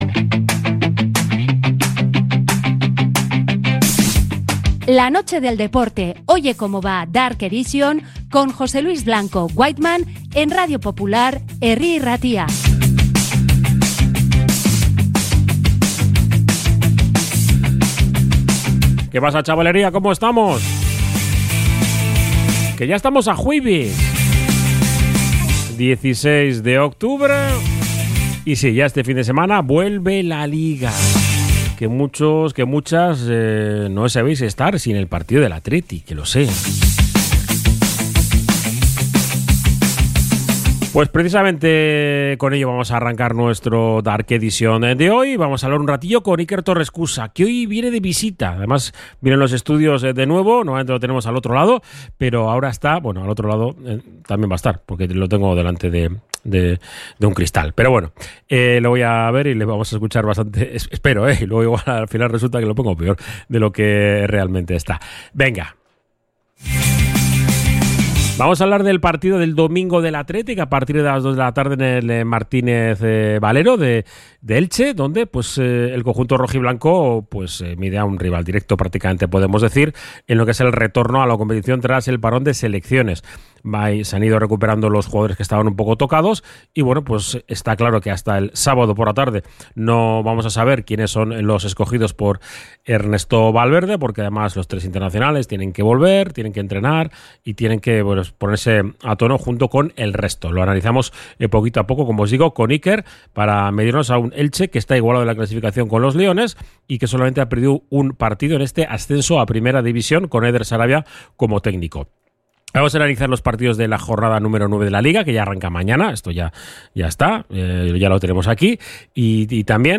De 22 a 23 horas con música nacida en el Mediterráneo